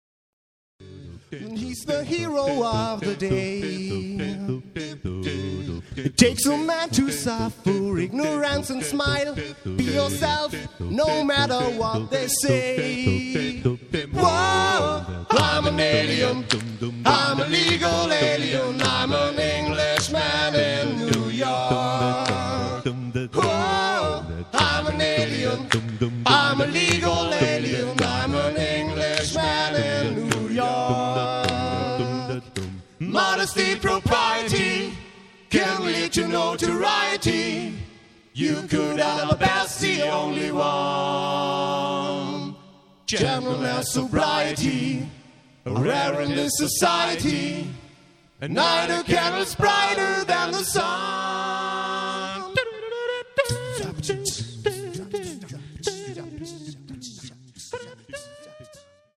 A-Cappella
live & authentisch.